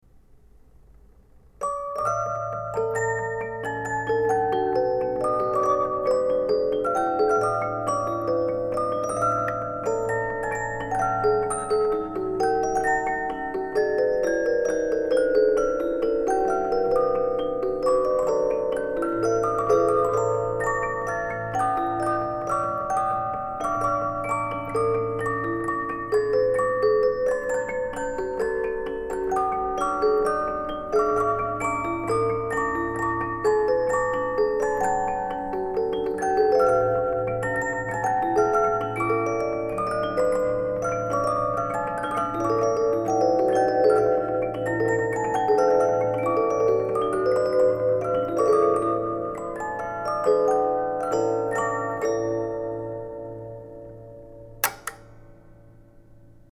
もう一つの課題となっているシリンダー・オルゴールはニコル・フレール社製のもので、製造番号31847から1855年頃の作品と思われます。
シリンダー長は33p（ 13インチ ）、櫛歯は97本、8曲入り、キーワインド）で幅51.5pのシンプルなケースに収容されています。